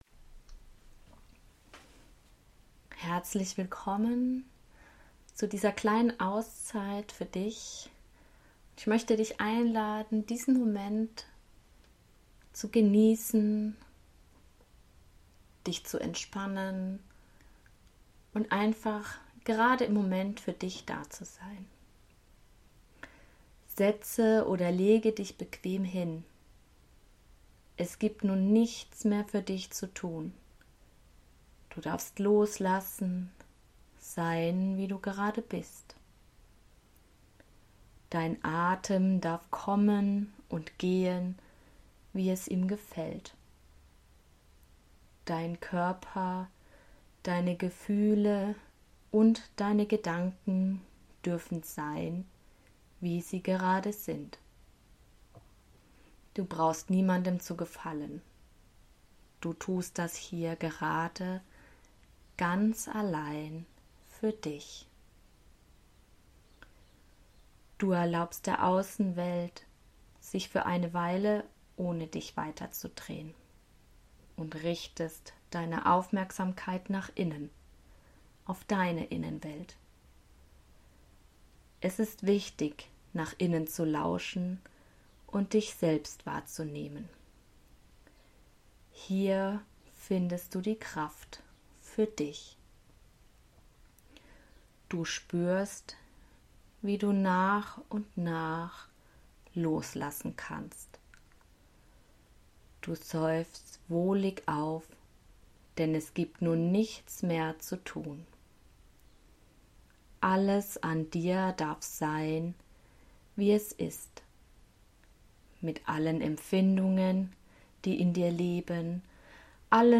Meditation-Mein-Wunsch-an-mich.mp3